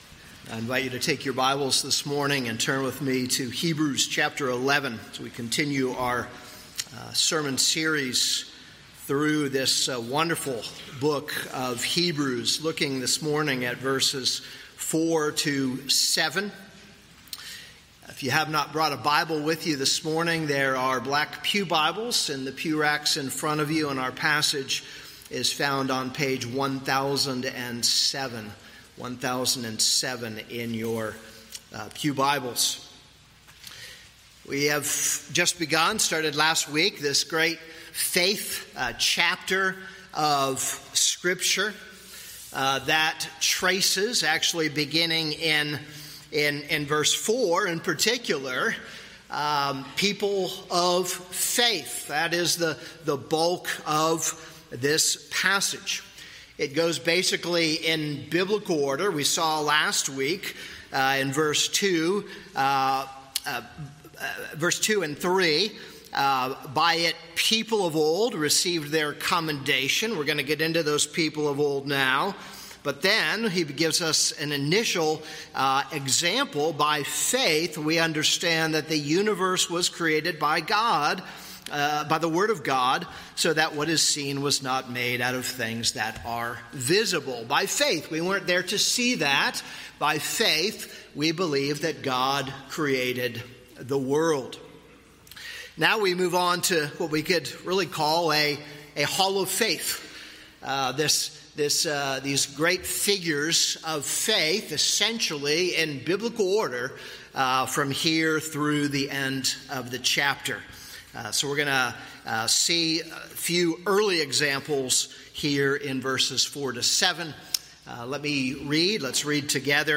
This is a sermon on Hebrews 11:4-7.